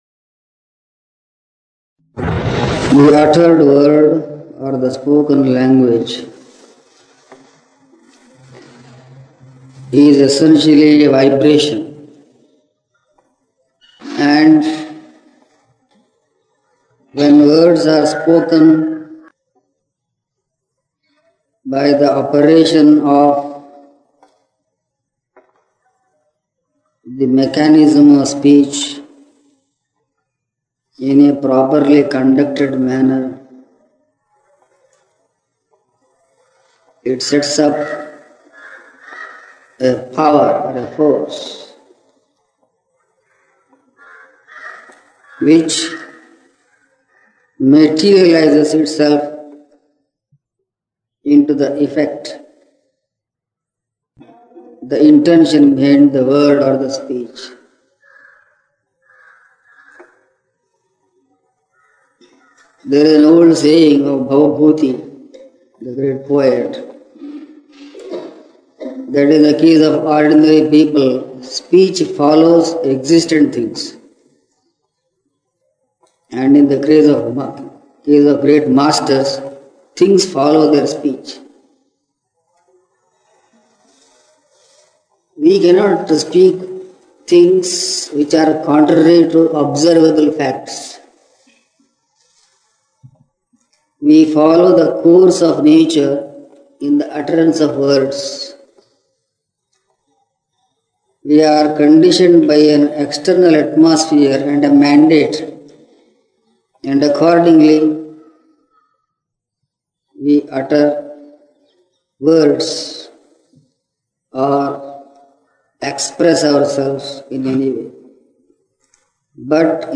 (Gita Jayanti Message spoken on December 26, 1982)